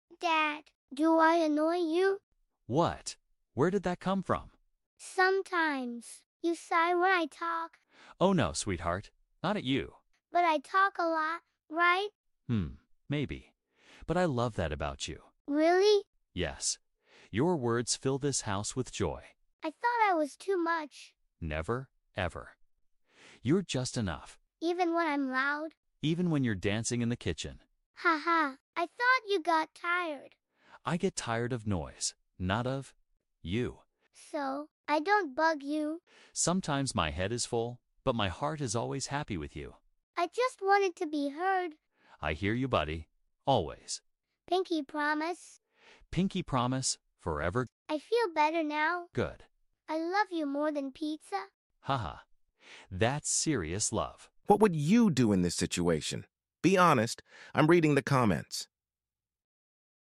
speaking practice through dialogue.